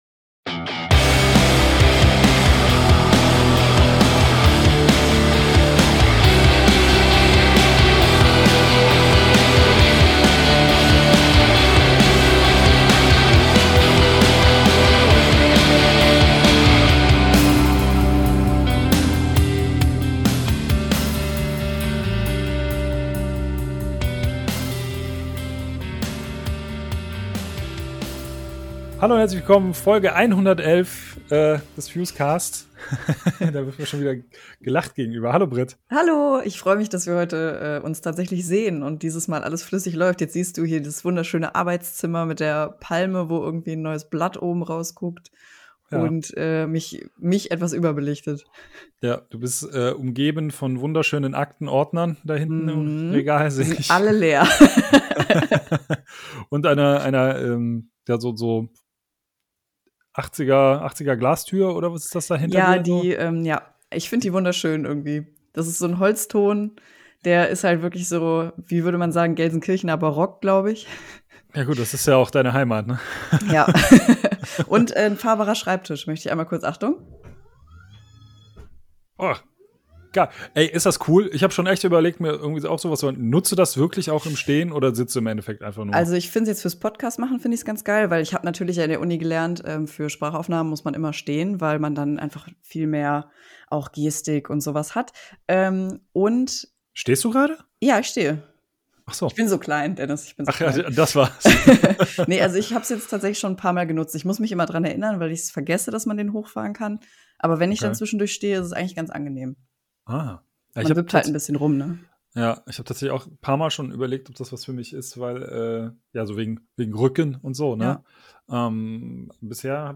Timecodes: 00:14:29 - Die News 00:42:42 - Interview MONOSPHERE 01:26:12 - Verabschiedung Ihr wollt das Heft nach Hause bekommen?